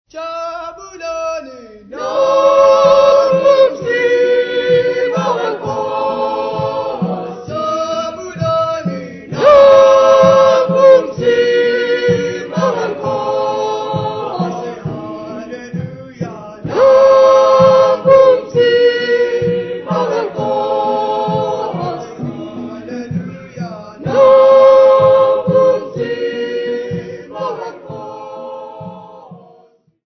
St Gabriel's Choir from Gugulethu
Choral music
Field recordings
Africa South Africa Rondebosch f-sa
sound recording-musical
Marimba Players from St Gabriel's in Gugulethu.
96000Hz 24Bit Stereo